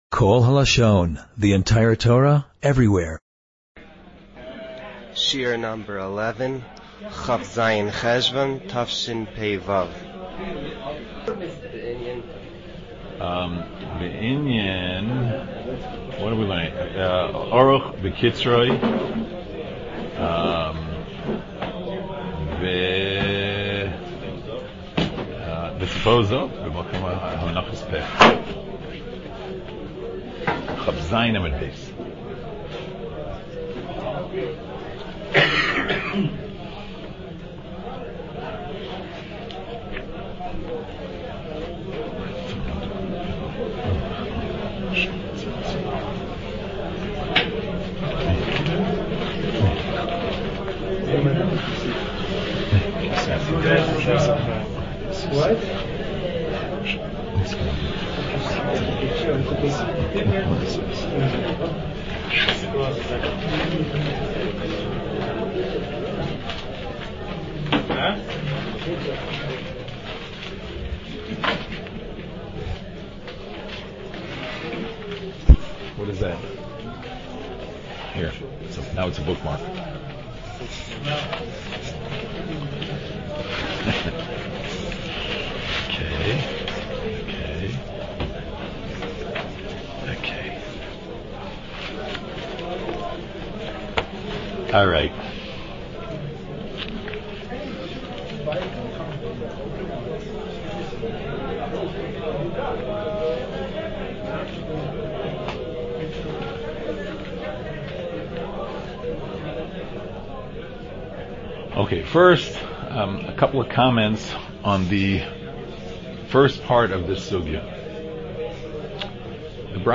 שיעור לא קל.